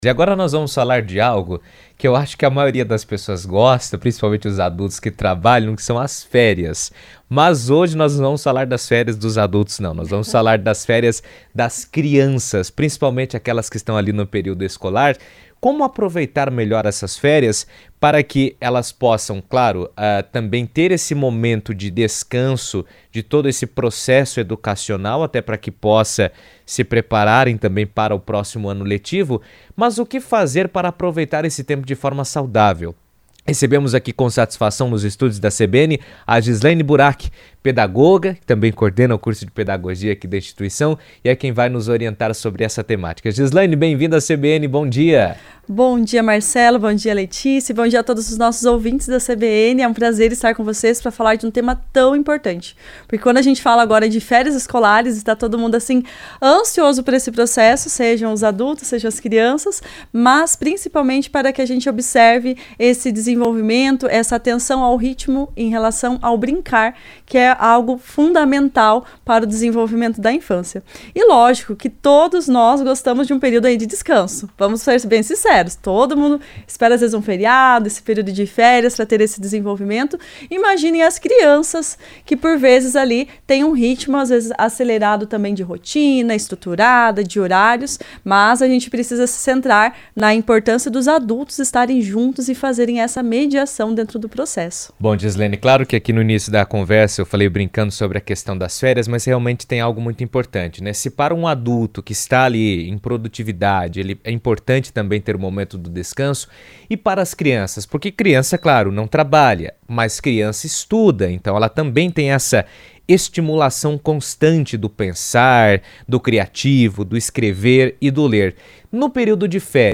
Editoriais